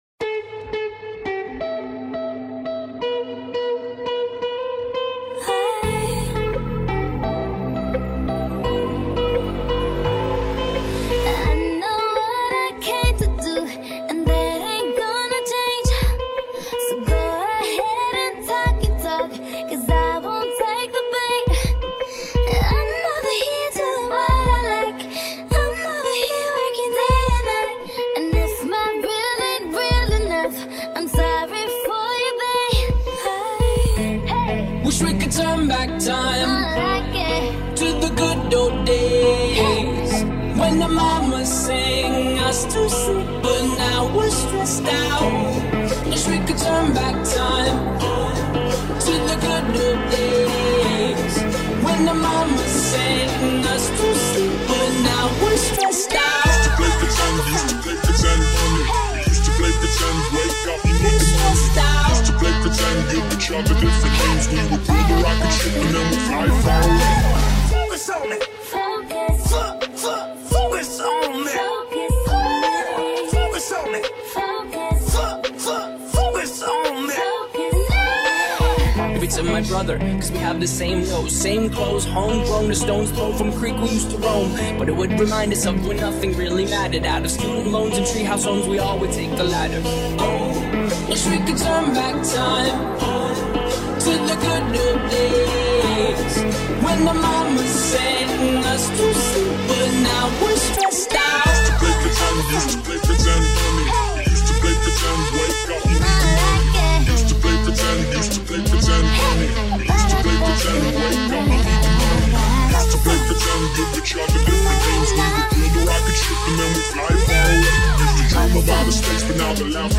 mashup